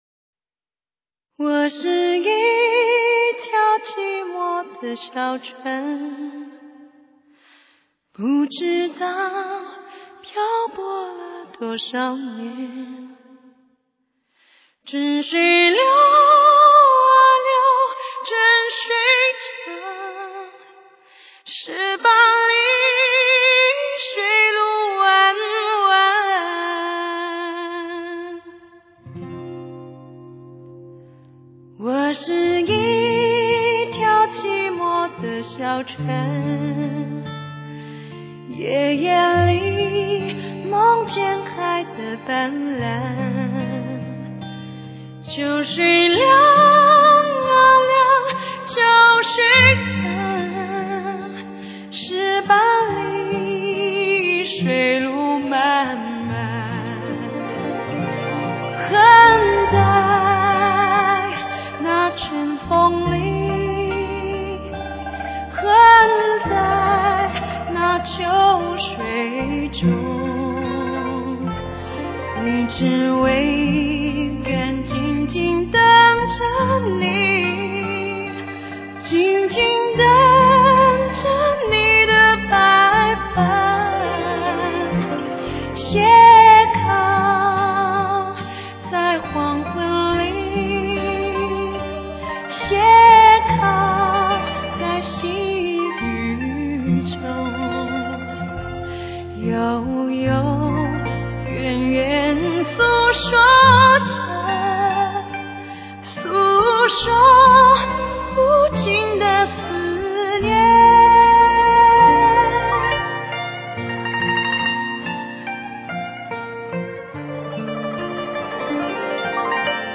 风中飘来的旋律，悠远和深情。
编配质朴唯美，录音自然逼真，为您真实再现人生的喜怒哀乐，是一张不可多得的发烧乐精选专辑。